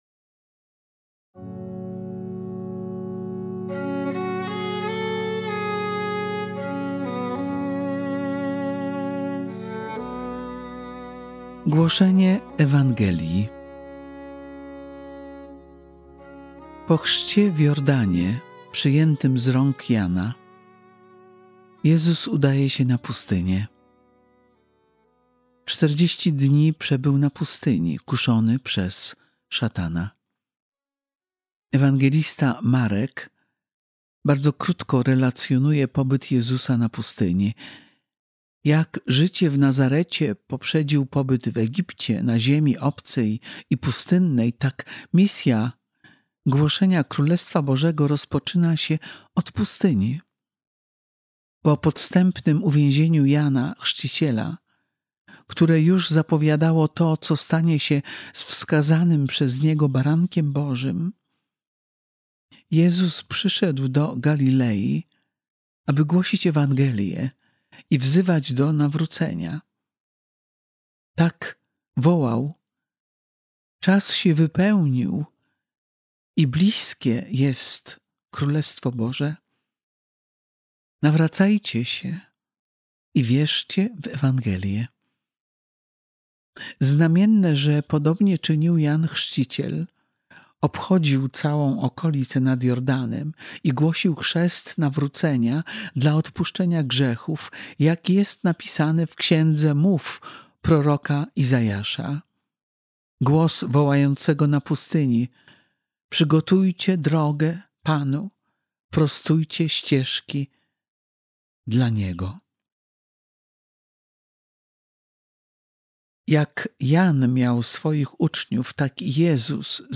Medytacja